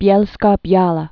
(byĕlskô-byälä, -byäwä)